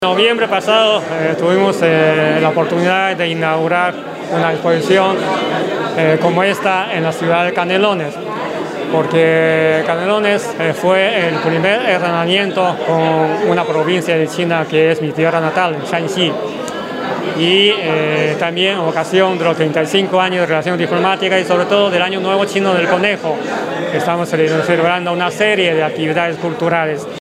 El Embajador de China en Uruguay, Wang Gang, explicó que “Canelones fue el primer hermanamiento con una provincia de China, que es mi tierra natal, Shanxi, y en ocasión de cumplirse los 35 años de relaciones diplomáticas es que estamos celebrando una serie de actividades culturales”, entre las que se encuentra la muestra.
embajador_de_china_en_uruguay_wang_gang.mp3